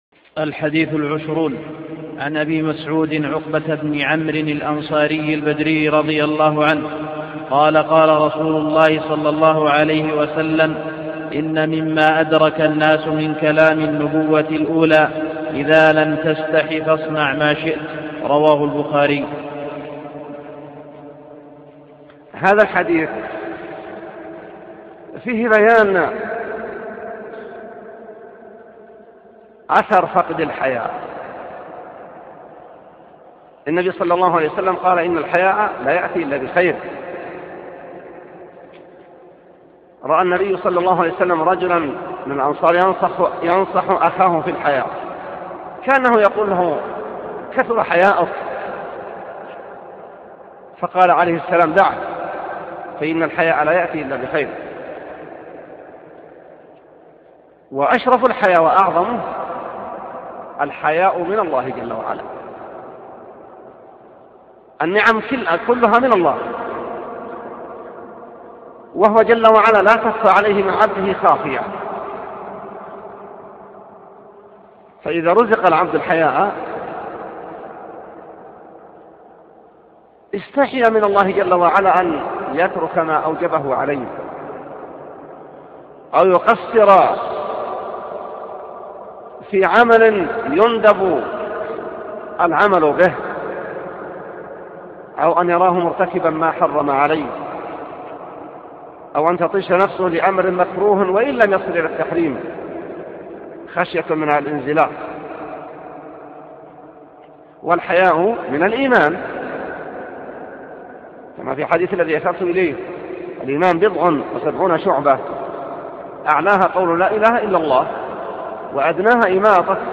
20 – شرح حديث إذا لم تستح فاصنع ما شئت – الشيخ : صالح اللحيدان